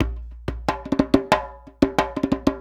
90 JEMBE3.wav